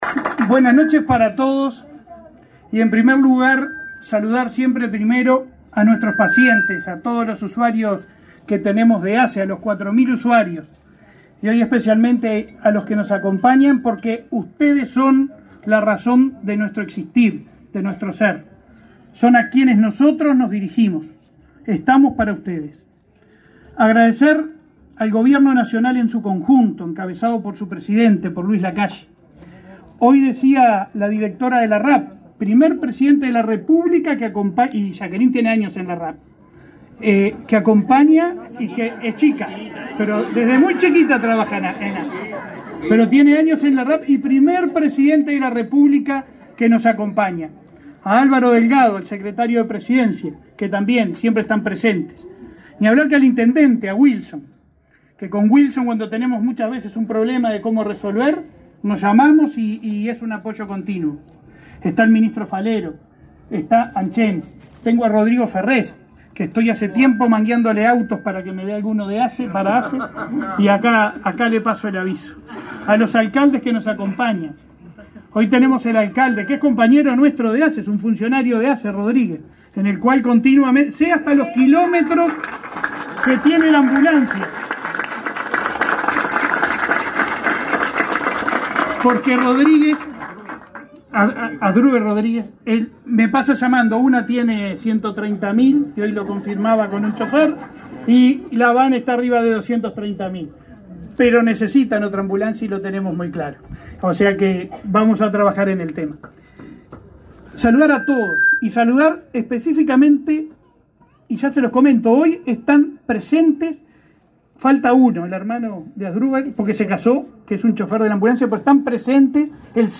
Declaraciones a la prensa del presidente de ASSE, Leonardo Cipriani
Declaraciones a la prensa del presidente de ASSE, Leonardo Cipriani 31/01/2023 Compartir Facebook X Copiar enlace WhatsApp LinkedIn El presidente de la República, Luis Lacalle Pou, participó, este 30 de enero, en la inauguración de obras de remodelación del Centro Auxiliar de San Gregorio de Polanco, dependiente de la RAP Tacuarembó. Tras el evento, el presidente de ASSE, Leonardo Cipriani, realizó declaraciones a la prensa.